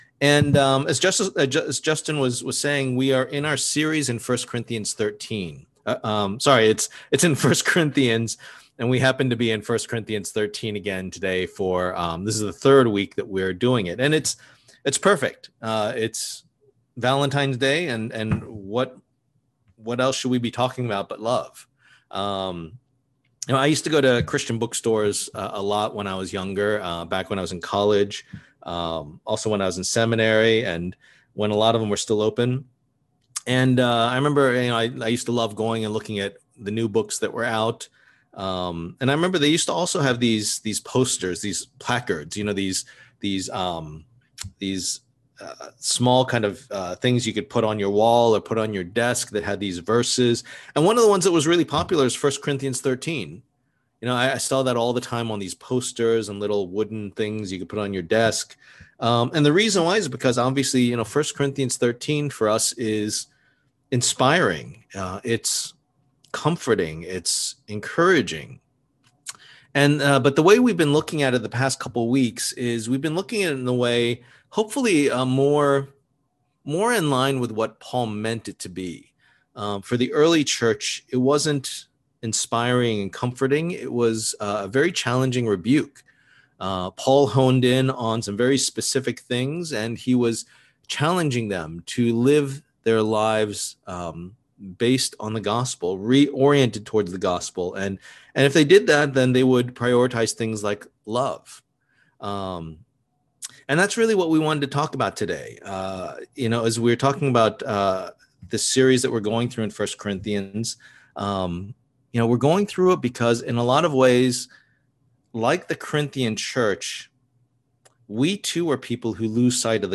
Passage: 1 Corinthians 13:1-13 Service Type: Lord's Day